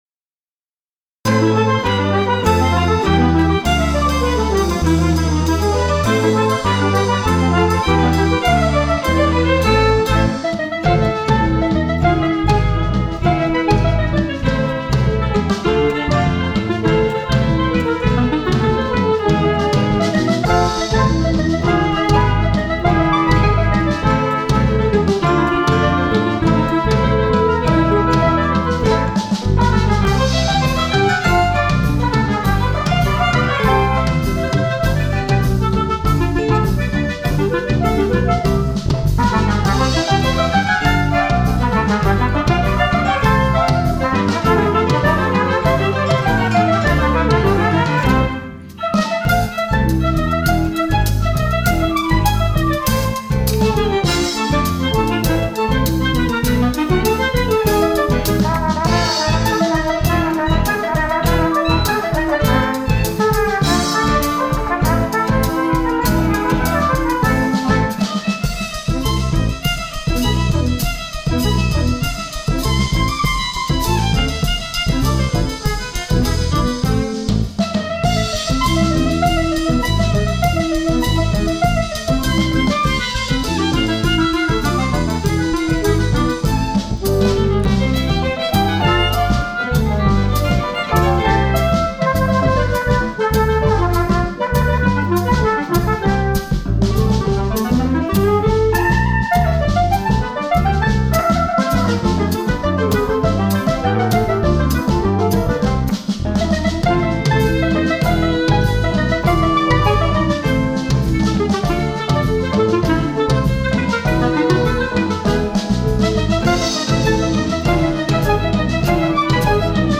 Un enregistrement réalisé par nos professeurs de musique :